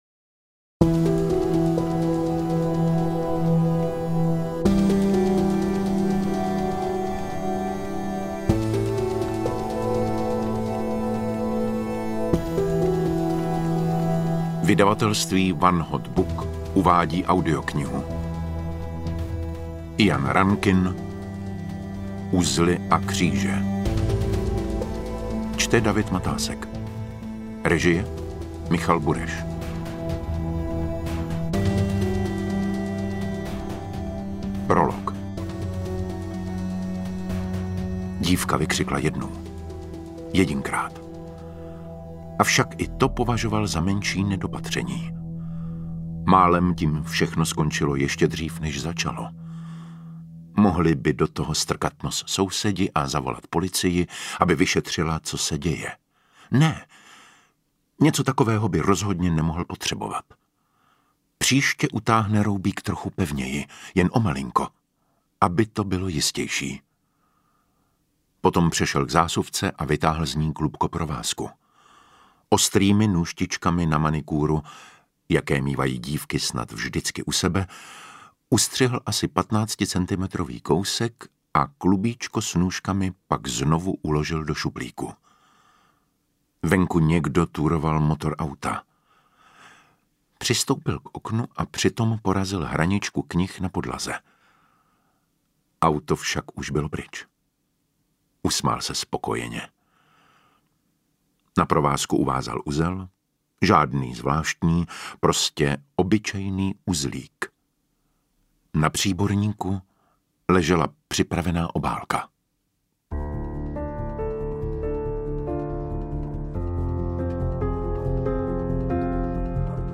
Interpret:  David Matásek
AudioKniha ke stažení, 25 x mp3, délka 7 hod. 21 min., velikost 394,0 MB, česky